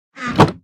Minecraft Version Minecraft Version 1.21.5 Latest Release | Latest Snapshot 1.21.5 / assets / minecraft / sounds / block / chest / close2.ogg Compare With Compare With Latest Release | Latest Snapshot
close2.ogg